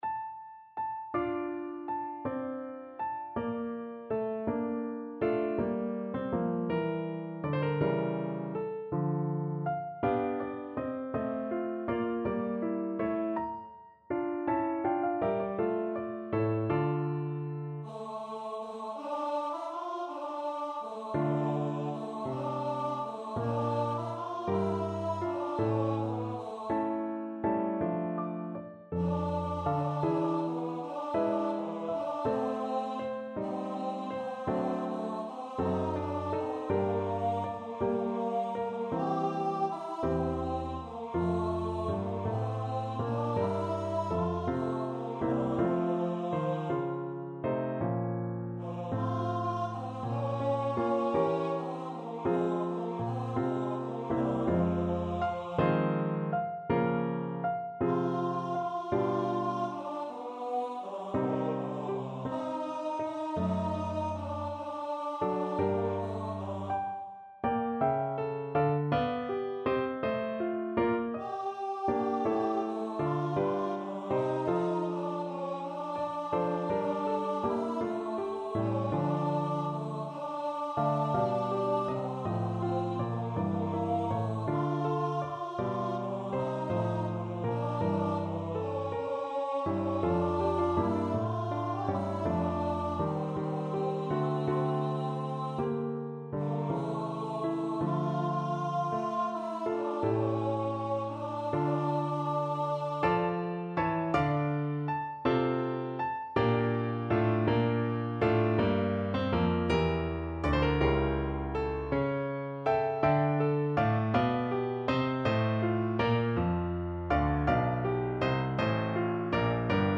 Free Sheet music for Tenor Voice
12/8 (View more 12/8 Music)
D minor (Sounding Pitch) (View more D minor Music for Tenor Voice )
~. = 54 Larghetto
Classical (View more Classical Tenor Voice Music)